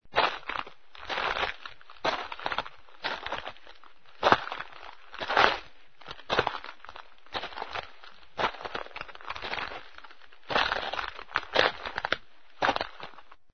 PASOS SOBRE GRAVA
Tonos EFECTO DE SONIDO DE AMBIENTE de PASOS SOBRE GRAVA
Pasos_sobre_grava.mp3